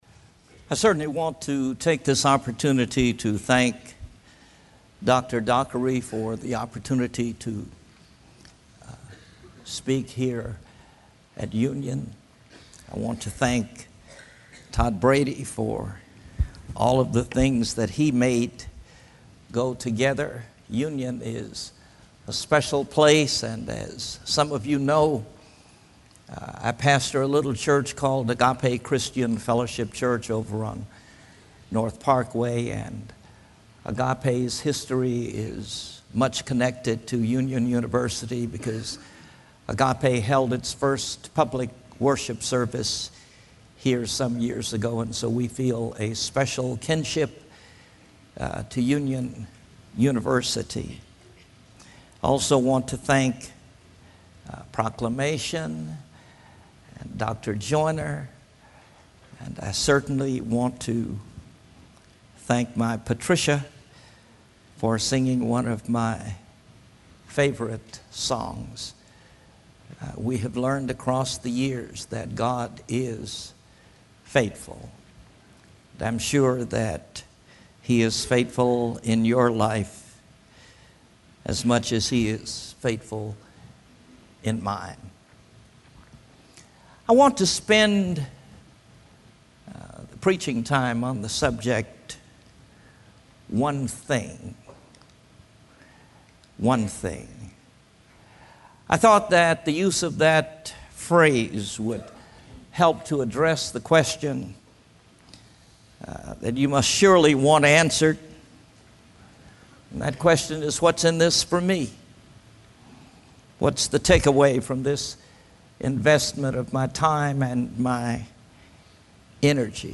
Chapels